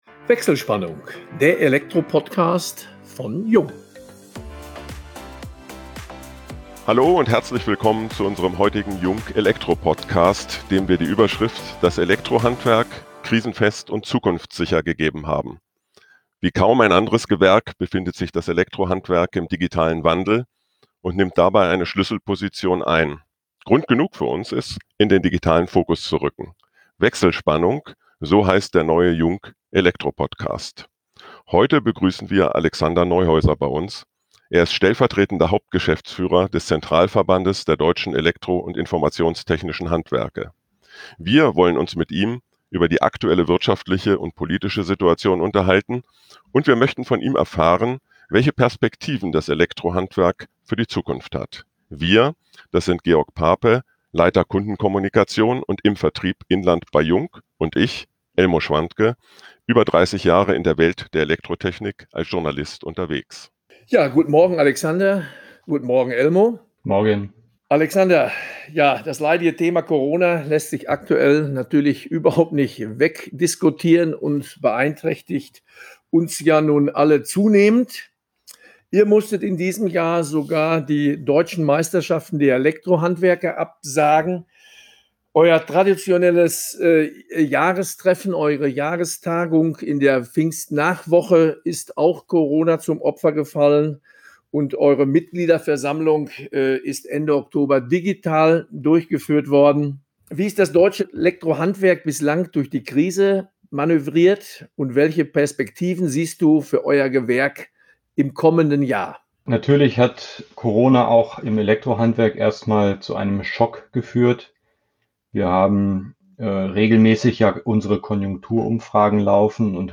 In vertrauensvollen Gesprächen diskutieren die Moderatoren